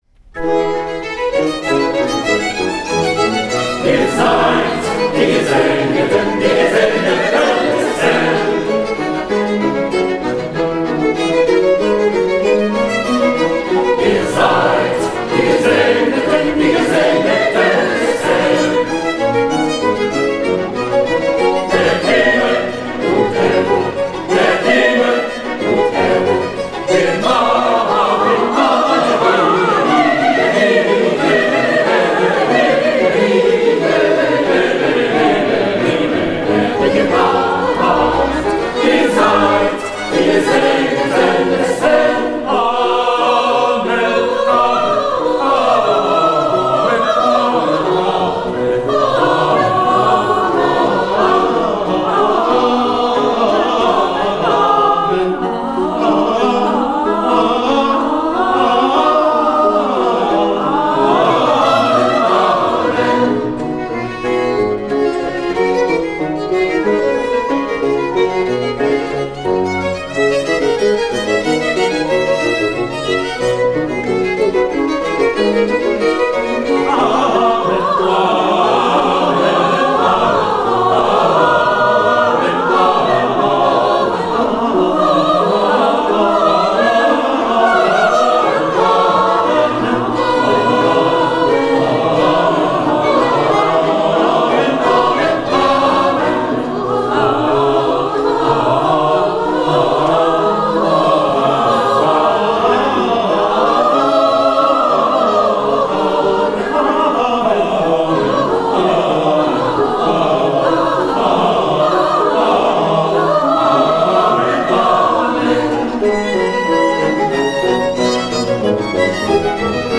Choeur n°5